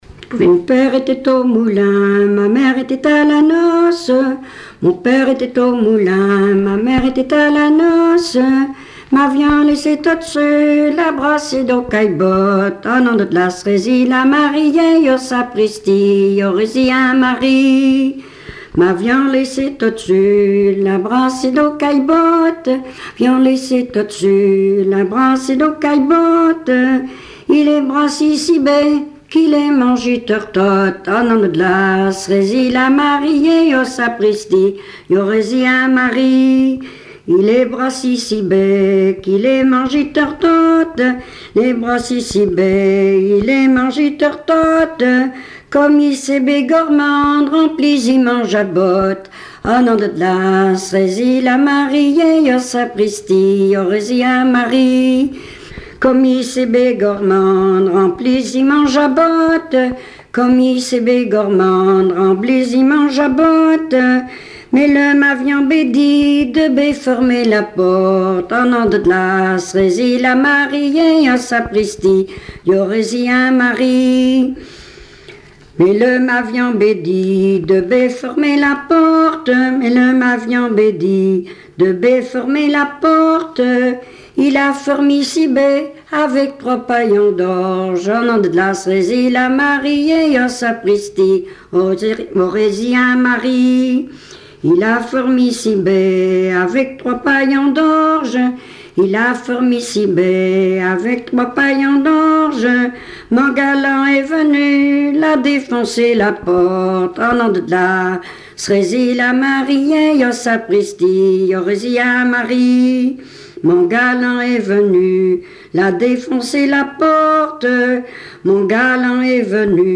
Langue Patois local
Genre laisse
Chansons traditionnelles et populaires
Pièce musicale inédite